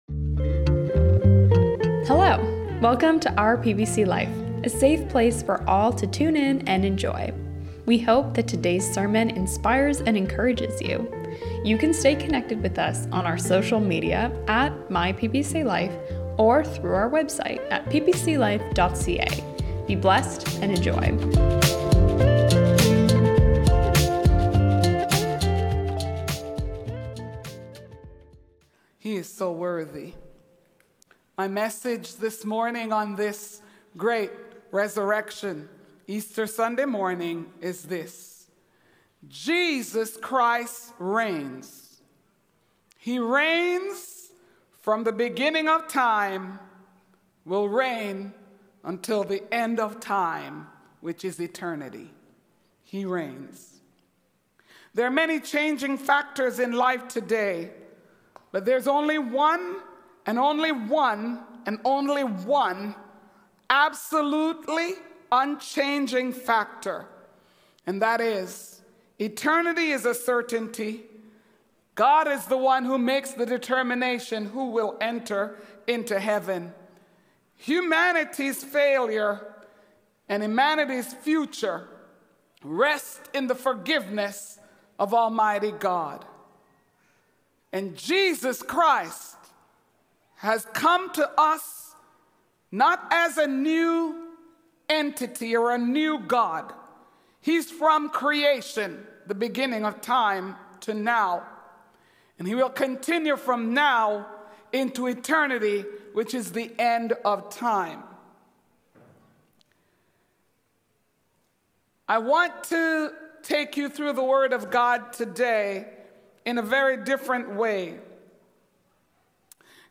This Sunday message comes to you live from The Arena in Pickering, which is was from our special outreach service.